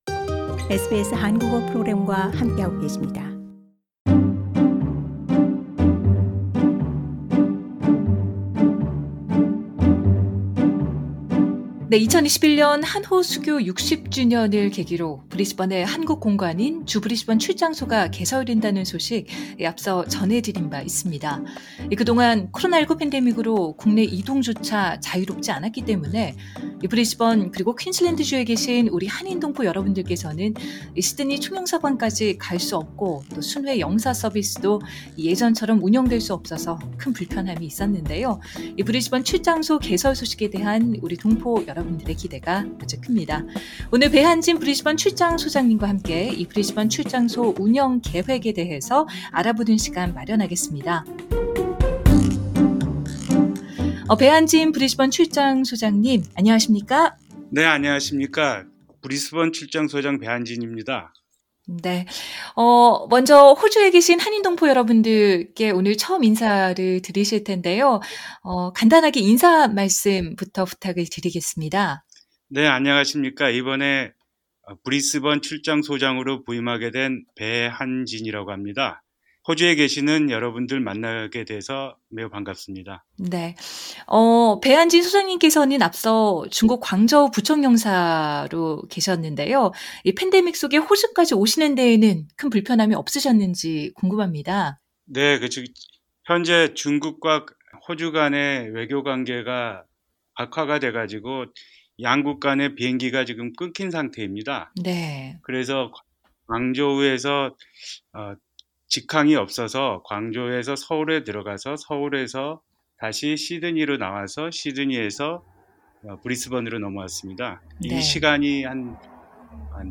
'단독 대담' 배한진 한국 공관 브리즈번 출장소장...“영사업무 착수했습니다”